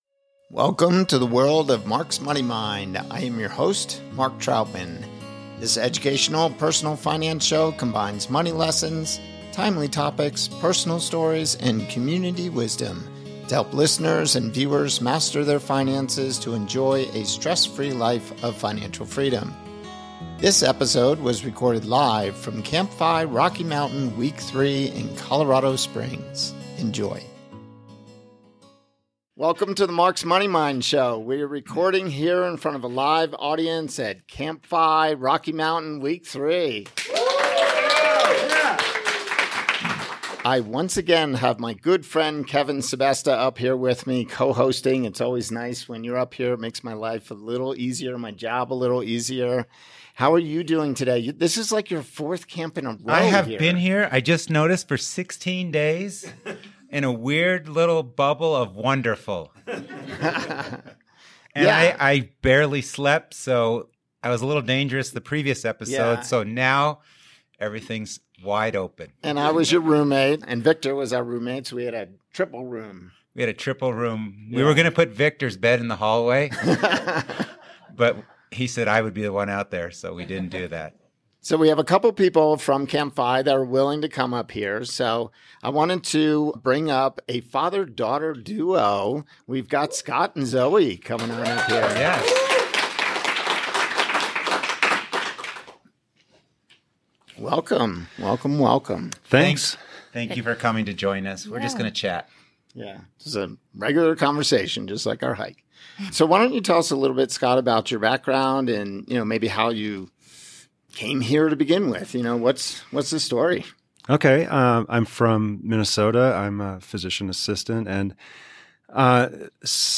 Life Transitions - Live from CampFI RM Week 3 | Episode 051